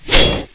knife_slash1_hunter.wav